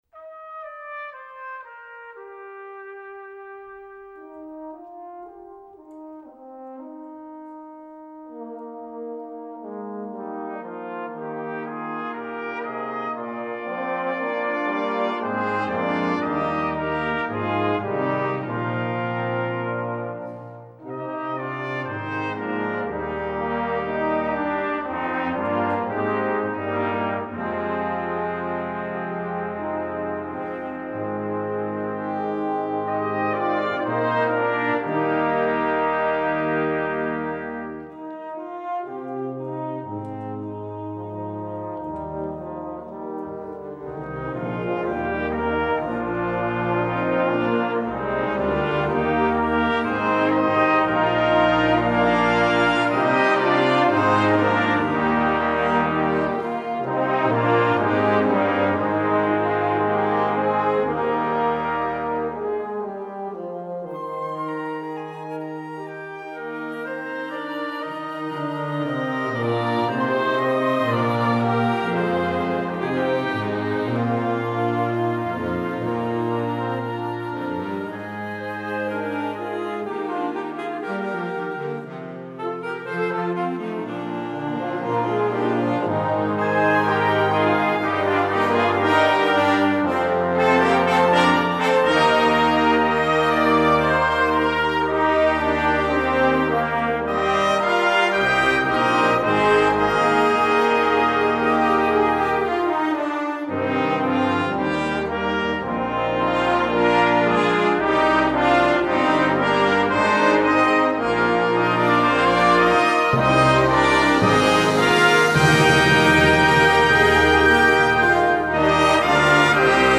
Gattung: A Hymn for Peace
Besetzung: Blasorchester